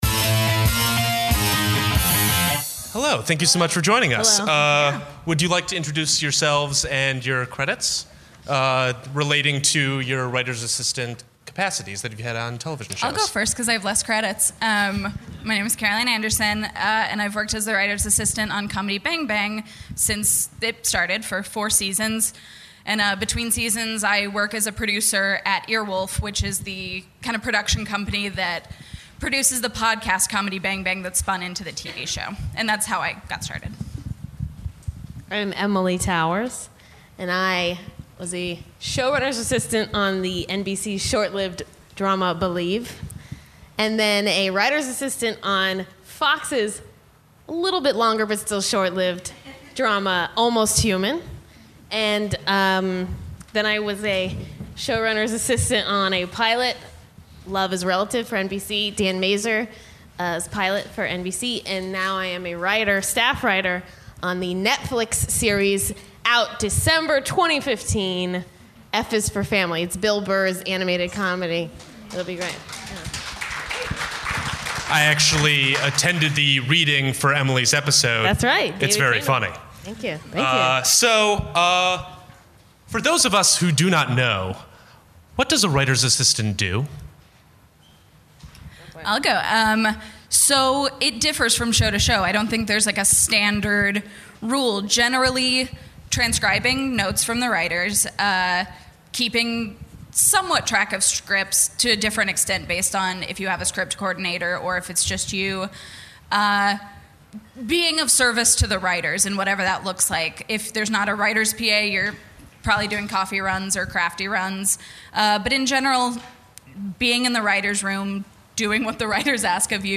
On a panel made up of both currently working writer's assistants and former writer's assistant now working as TV writers, we hope to to go in depth on the do's and don'ts of the job, the paths that lead to their position, and offer a Q&A session to provide advice for young writers still on their way up in the televis
The New Brain Panel series is held every Saturday on the Inner Sanctum stage at UCB Sunset in Los Angeles, CA. UCB Comedy hosts the third Saturday of each month.